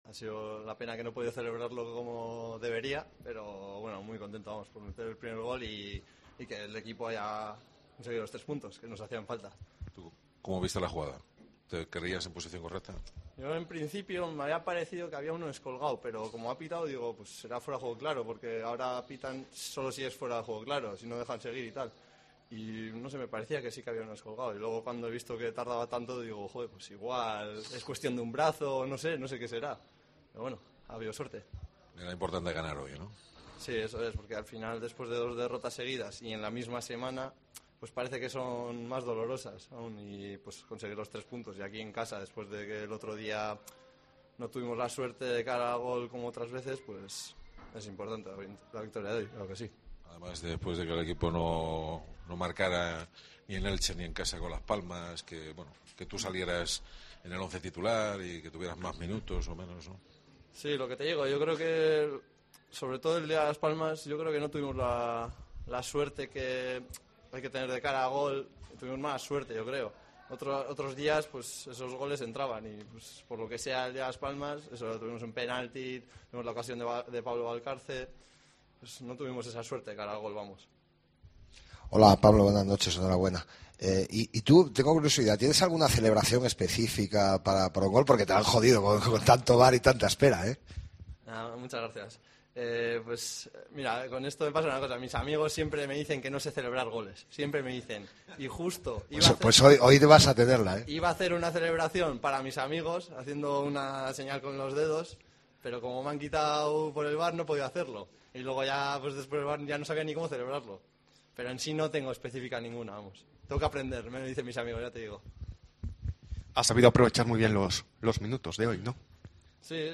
Escucha aquí las palabras de los dos futbolistas blanquiazules